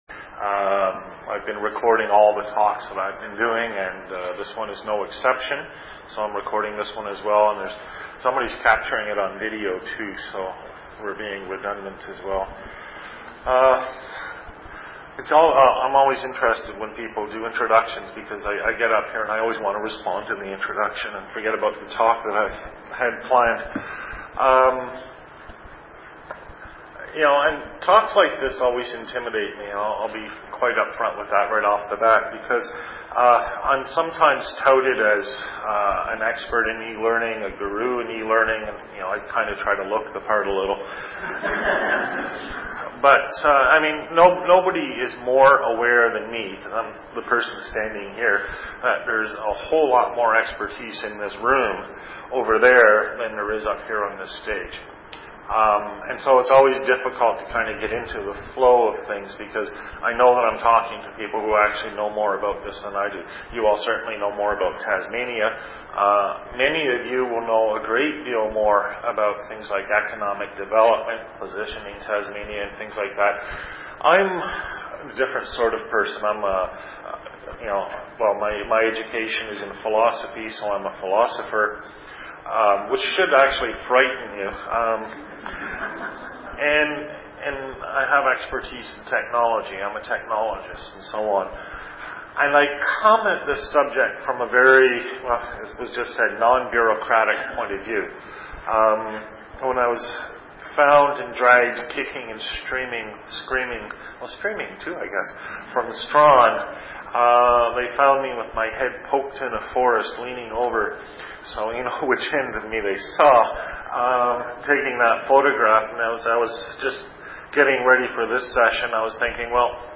This full day session was a wide ranging discussion centered around four major themes: the role of government, the role of industry, the role of education, and a wrap up, bringing it all together.